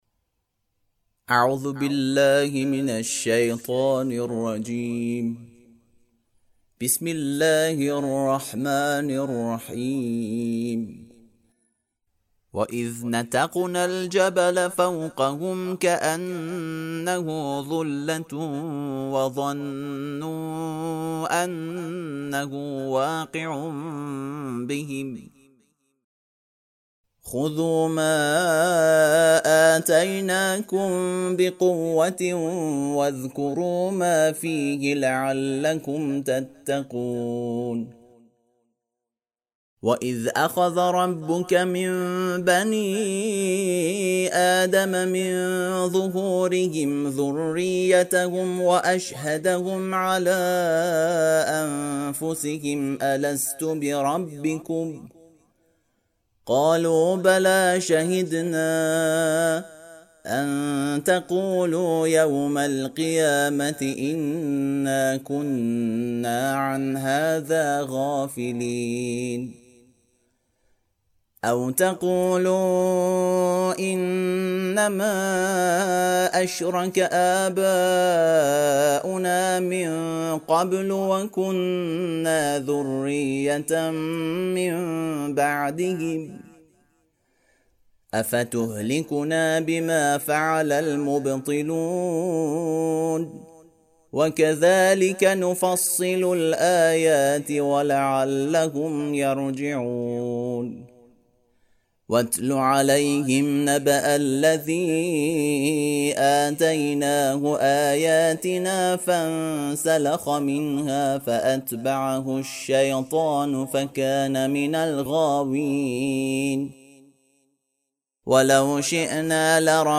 ترتیل صفحه ۱۷۳ سوره مبارکه اعراف(جزء نهم)
ترتیل سوره(اعراف)